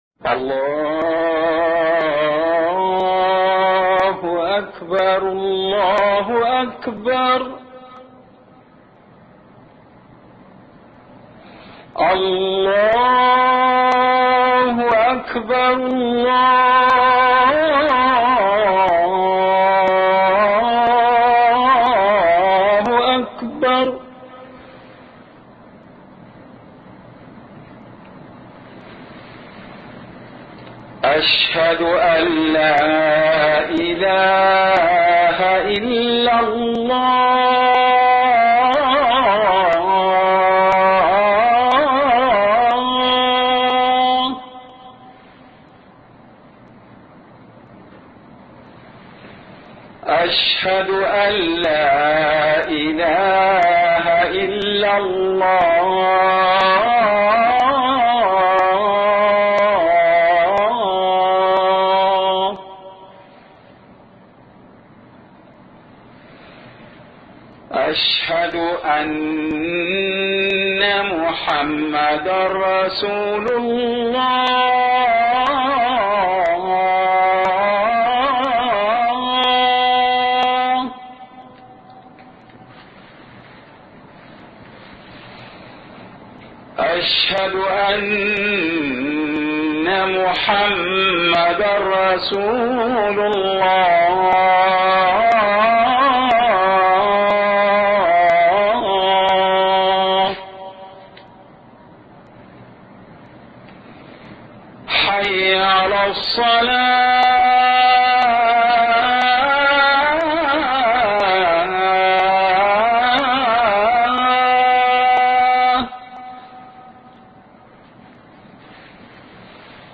أذان